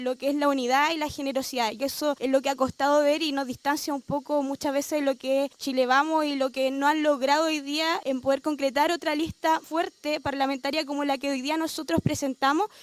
La presidenta de esta última colectividad, la diputada Sara Concha, señaló que en la elección de 2024 a Chile Vamos le “faltó generosidad” y eso derivó en una división de fuerzas que hoy tiene a los dos bloques de la derecha negociando por separado.